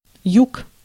Ääntäminen
Synonyymit sud Ääntäminen France: IPA: /mi.di/ Haettu sana löytyi näillä lähdekielillä: ranska Käännös Ääninäyte Substantiivit 1. юг {m} (jug) Muut/tuntemattomat 2. по́лдень {m} (pólden) Suku: m .